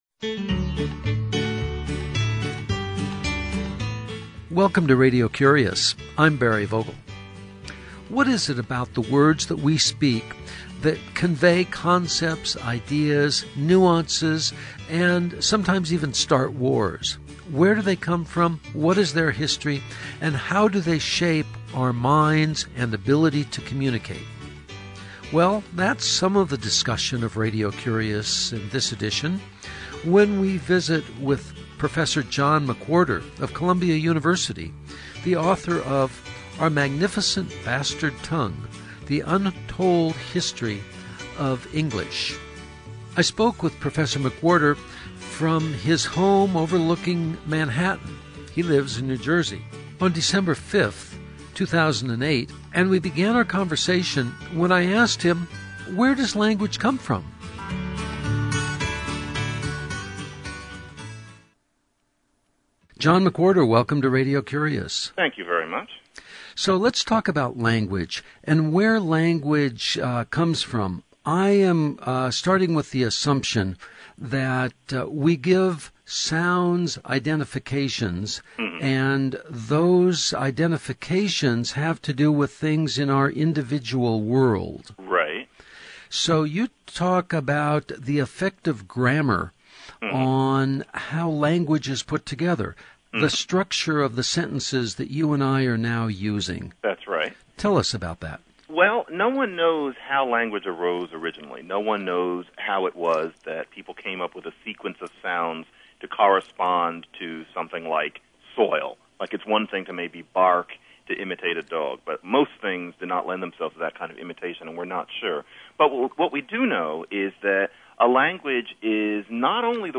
Radio Curious visits the Heard Museum in Phoenix, Arizona.